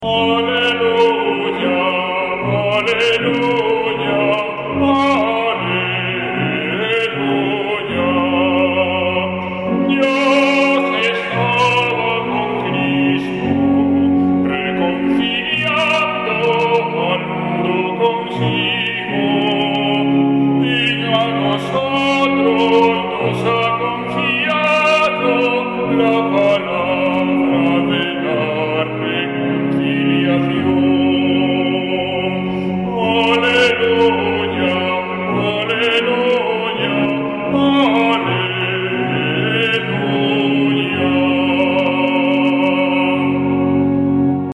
domingo24caleluya.mp3